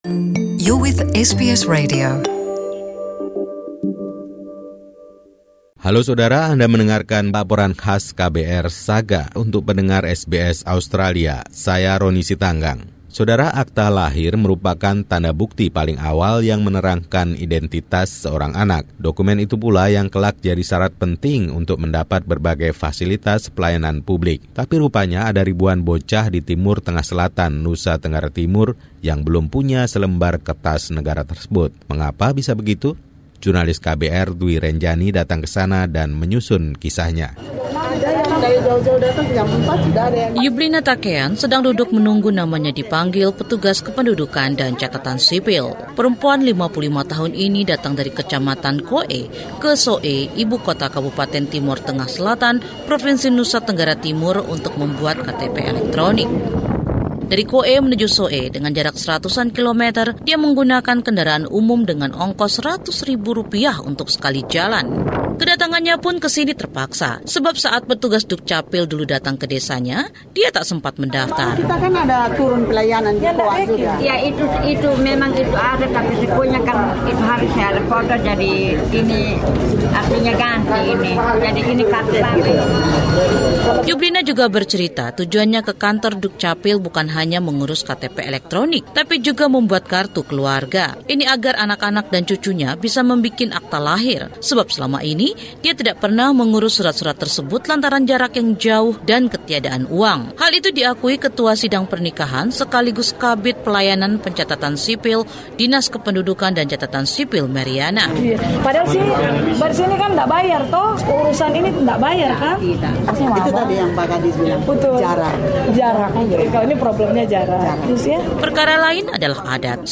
Laporan khusu tim KBR 68H ini mengisahkan kesulitan yang masih dialami oleh kawula muda NTT.